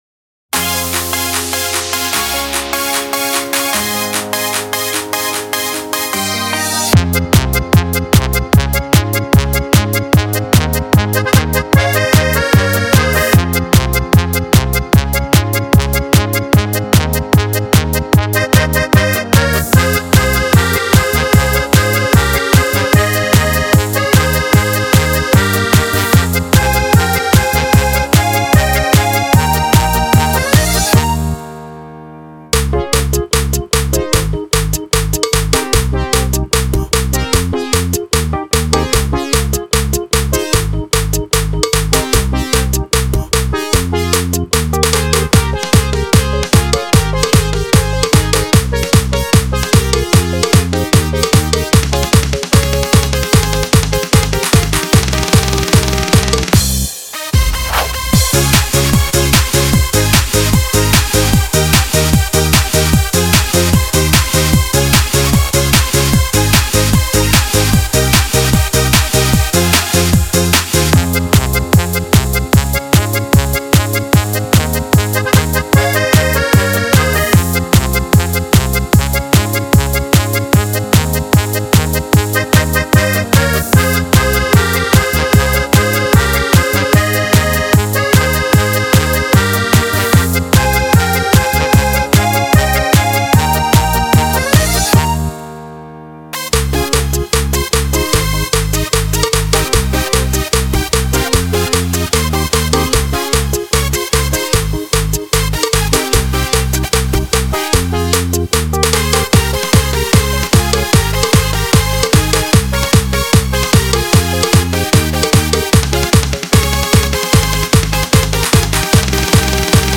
Zonder backing